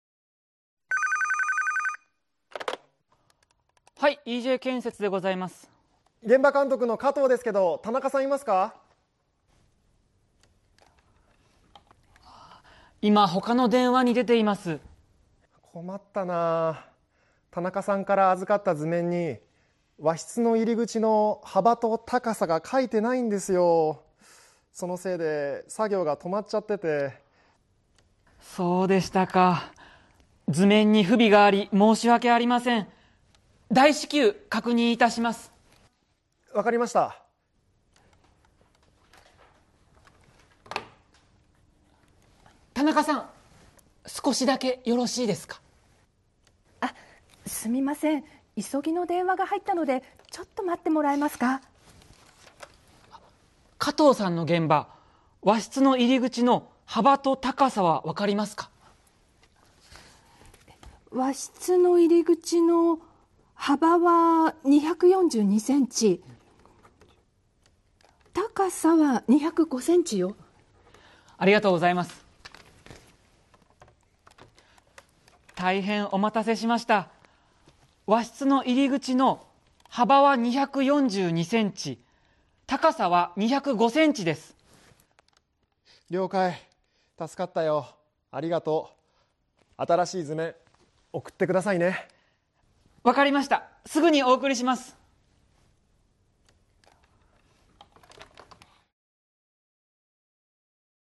Fielding an urgent request on the phone
Role-play Setup
A foreman calls from a jobsite with an urgent issue—the blueprints are missing measurements for the entrance to a Japanese-style room, and work has stopped.